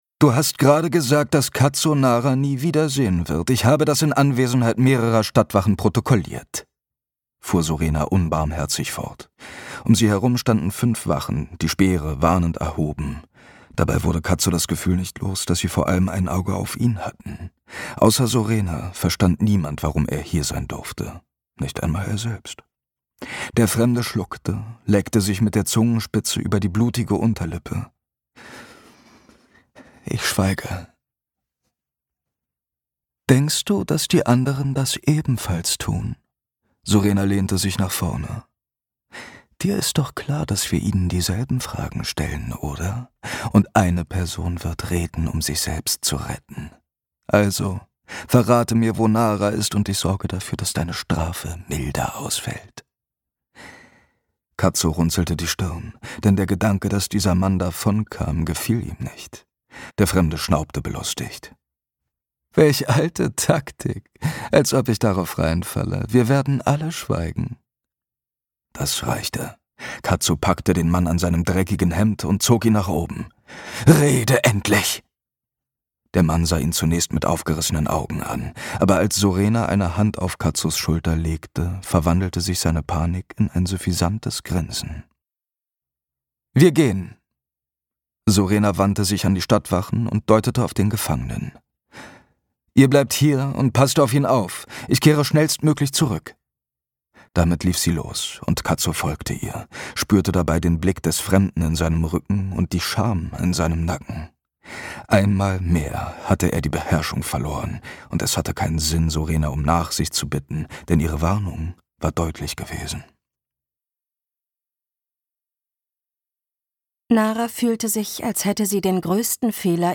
Intensiv und nuanciert navigiert das Ensemble durch den tödlichen Wettkampf, die politischen Intrigen und die emotionalen Konflikte – ein dramatisches und tiefgründiges Hörerlebnis, das die Reihe fulminant fortführt.